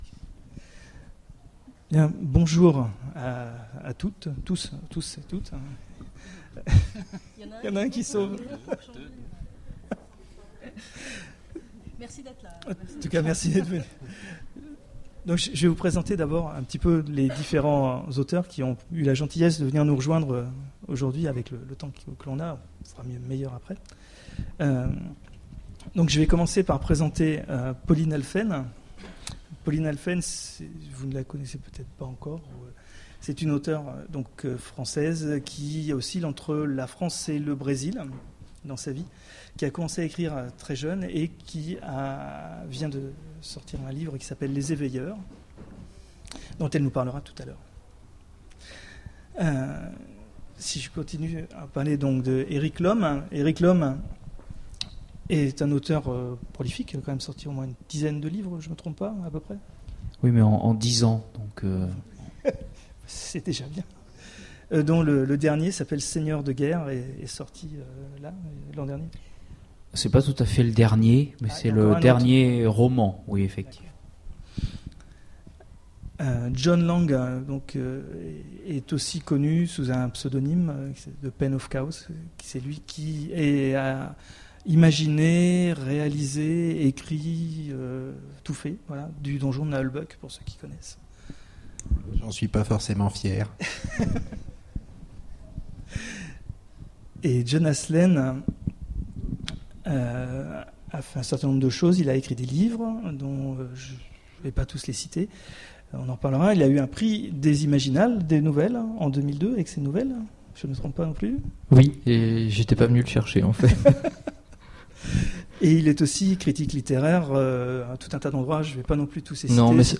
Voici l'enregistrement de la conférence Ecrire pour les jeunes... et être aussi lu par les adultes ? aux Imaginales 2010